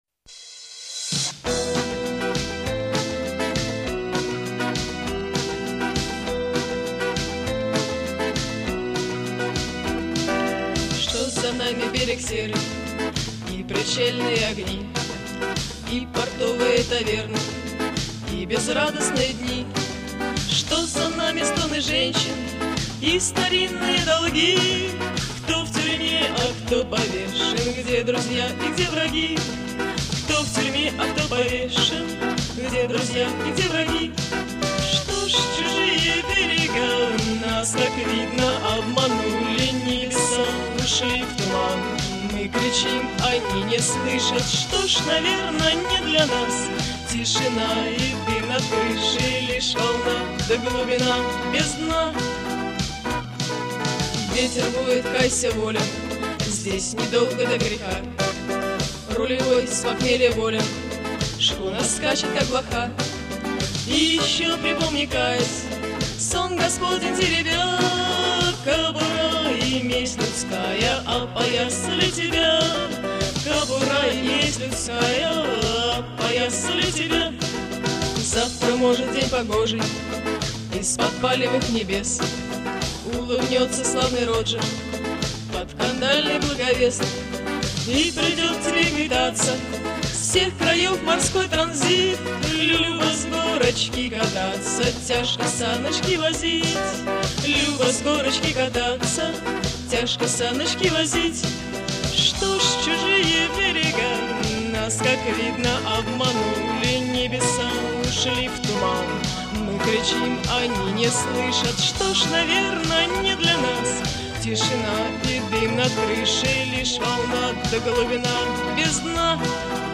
Жанр: авторская песня
Гитара